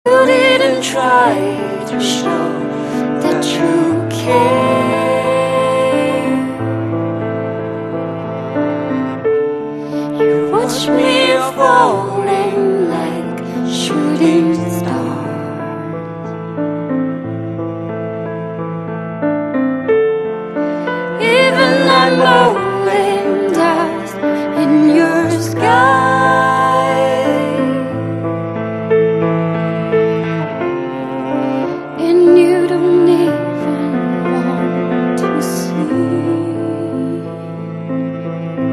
M4R铃声, MP3铃声, 欧美歌曲 44 首发日期：2018-05-15 19:54 星期二